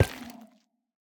Minecraft Version Minecraft Version latest Latest Release | Latest Snapshot latest / assets / minecraft / sounds / block / sculk_catalyst / step2.ogg Compare With Compare With Latest Release | Latest Snapshot
step2.ogg